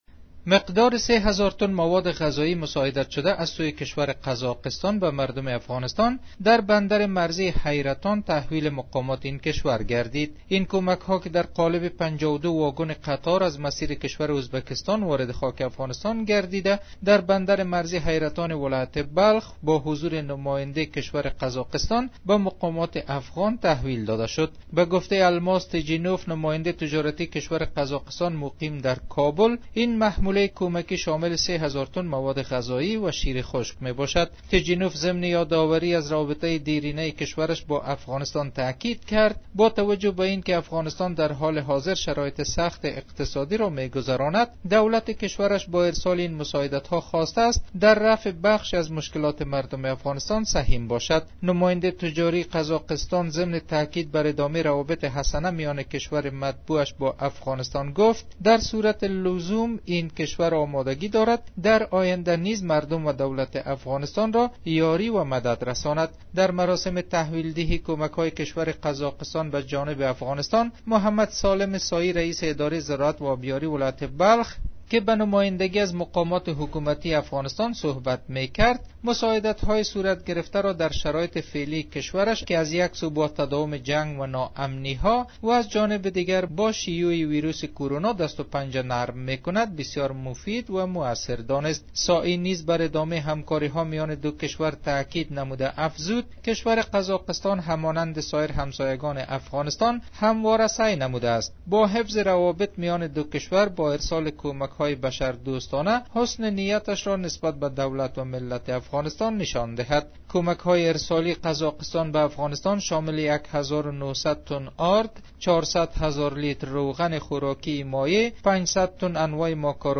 دولت قراقستان برای کمک به مردم افغانستان 3000 تن موادغذایی را در بندر مرزی حیرتان ولایت بلخ به مقامات افغان تحویل داد گزارش تکمیلی